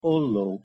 Pronunciation Hu Olló+(1) (audio/mpeg)